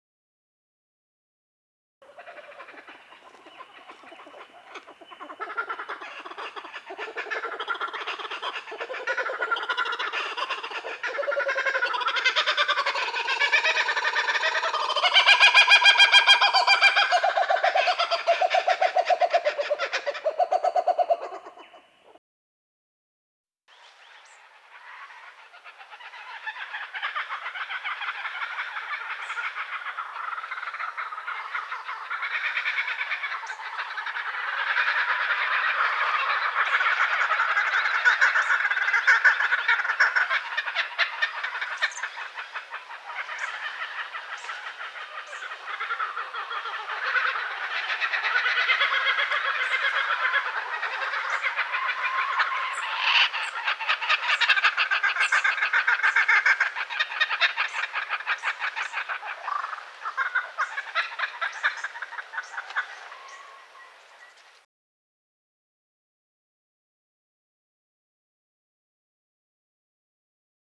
Unique Australian Bird Sounds
laughing kookaburra
10-laughing-kookaburra.mp3